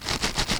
■カサカサ
マイクで録音し、Wpaseで、ビットや、周波数を下げ、低容量にしました。
ソースはマイクの近くで紙をクシャクシャしました。